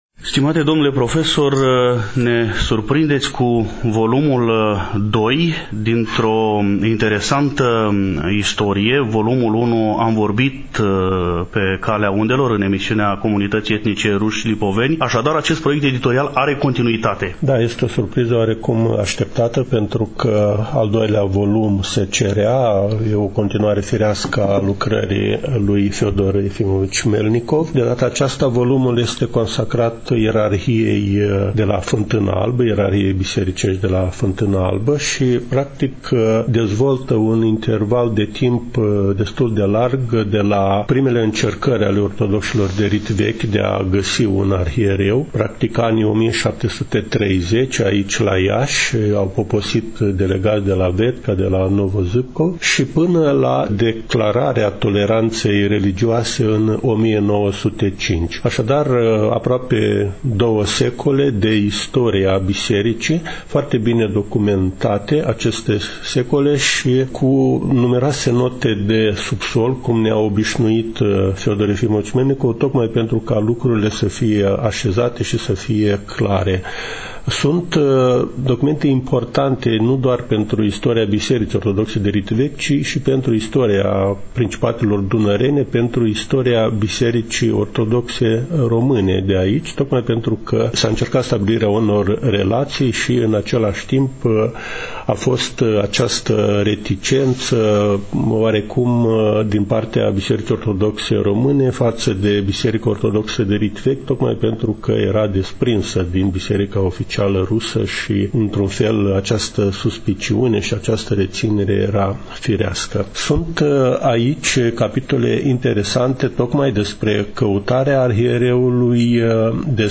stăm de vorbă cu domnul profesor universitar